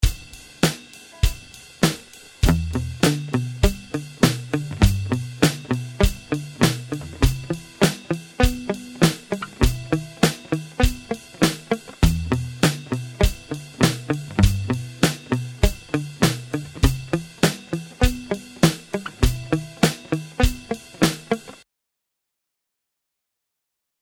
However you can also apply palm muting to the notes to create a quieter sound where the notes are more separated due to them not ringing out.
Arpeggios With Palm Muting | Download
palmmute.mp3